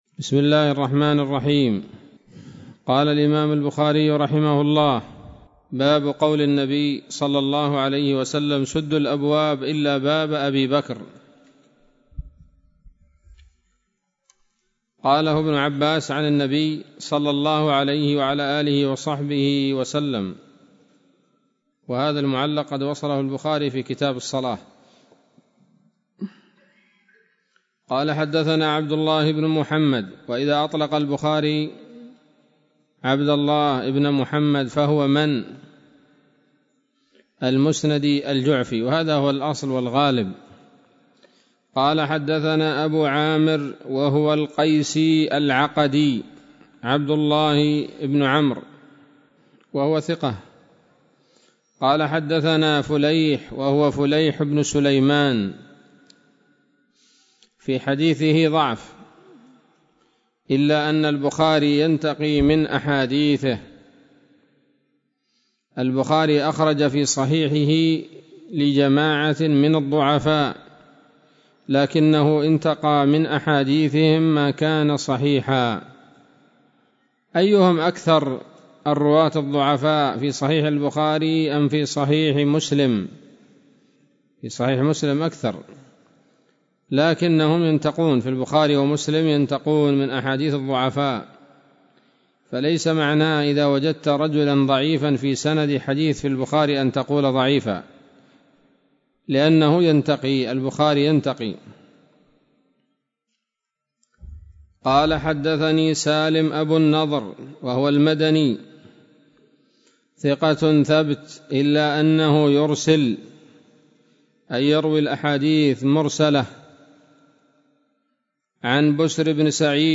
الدرس الثالث من كتاب فضائل أصحاب النبي صلى الله عليه وسلم من صحيح البخاري